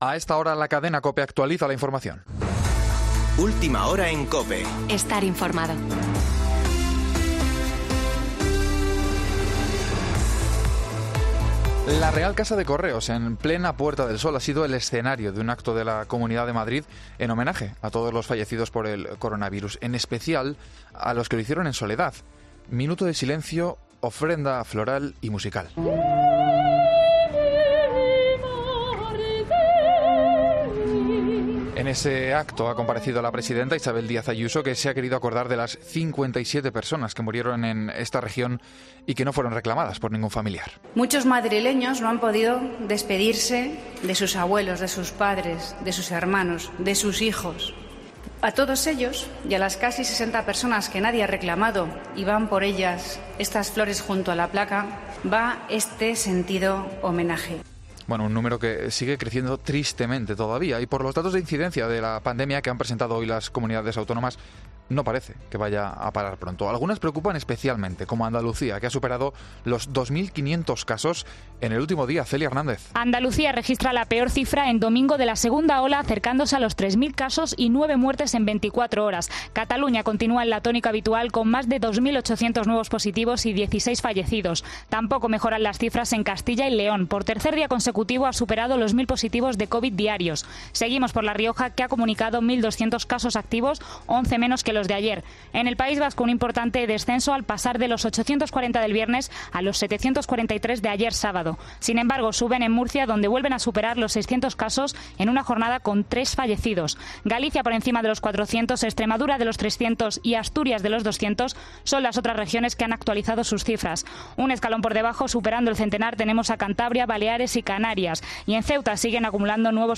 Boletín de noticias de COPE del 18 de Octubre de 2020 a las 18.00 horas